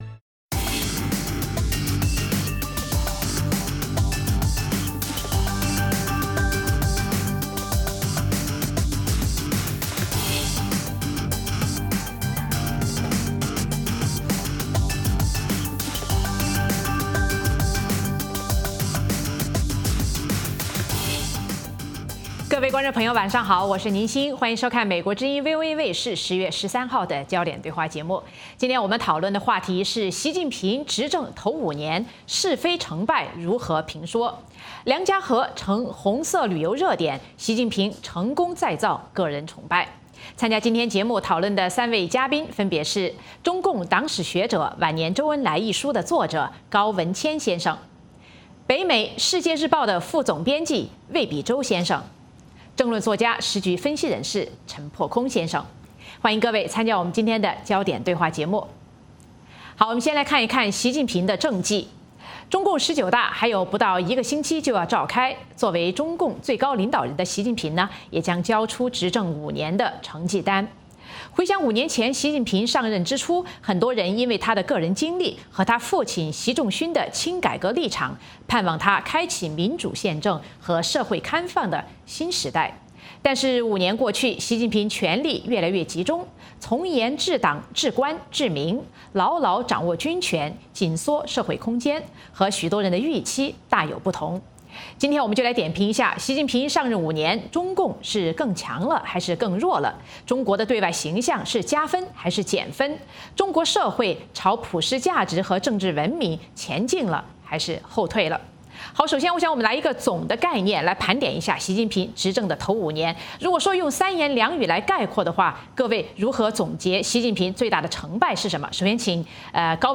美国之音中文广播于北京时间每周五晚上9-10点播出《焦点对话》节目。《焦点对话》节目追踪国际大事、聚焦时事热点。邀请多位嘉宾对新闻事件进行分析、解读和评论。